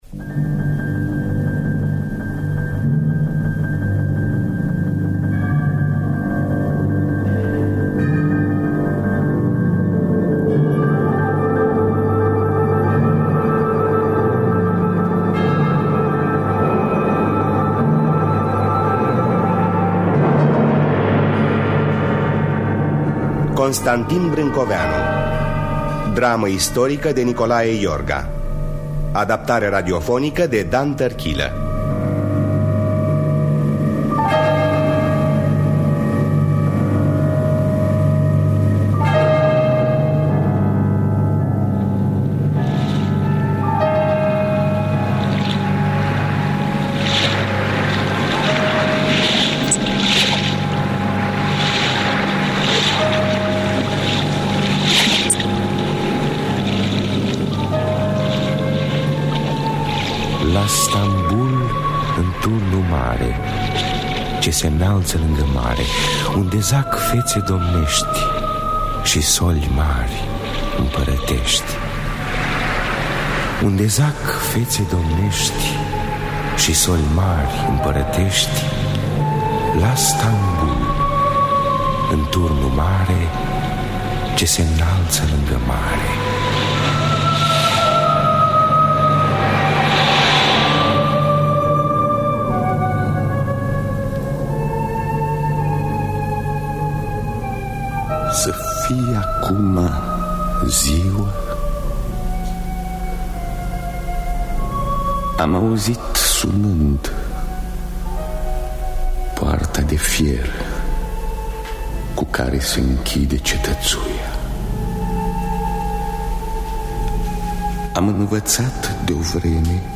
Adaptare radiofonica de Dan Tărchilă.
Înregistrare din anul 1969.